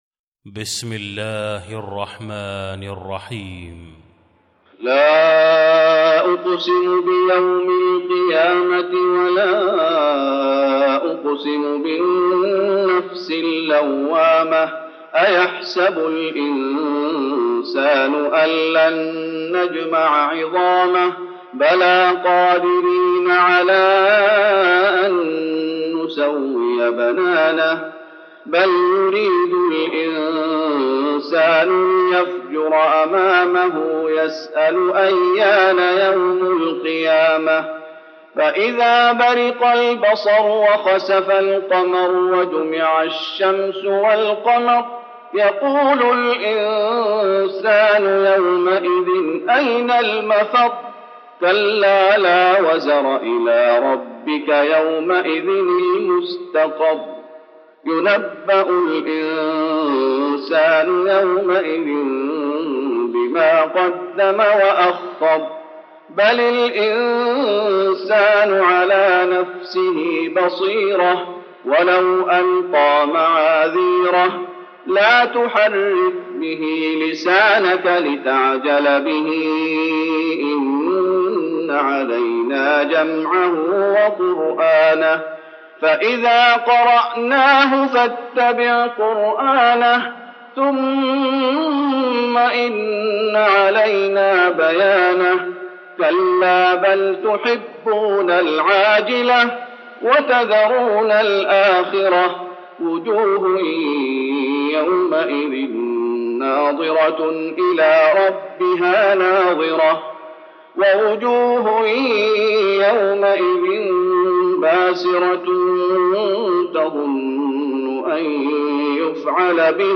المكان: المسجد النبوي القيامة